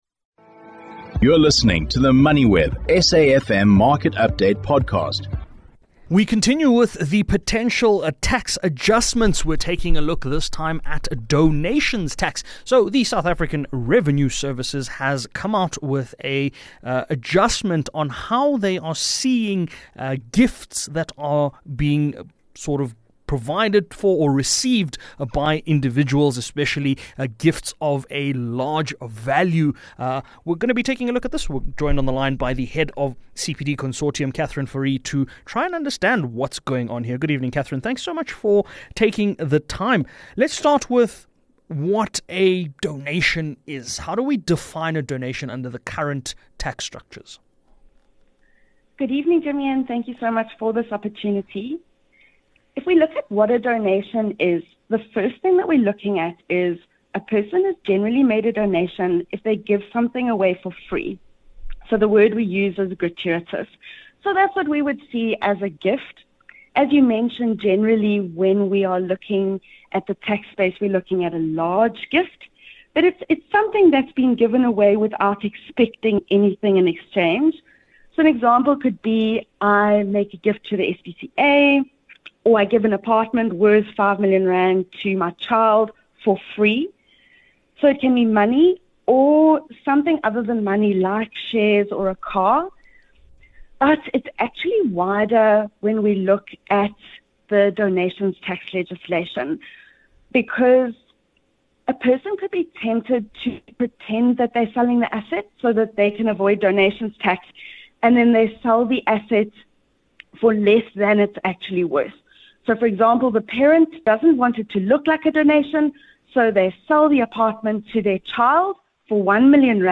The programme is broadcasted Monday to Thursday nationwide on SAfm (104 – 107fm), between 18:00 and 19:00.